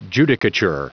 Prononciation du mot judicature en anglais (fichier audio)
Prononciation du mot : judicature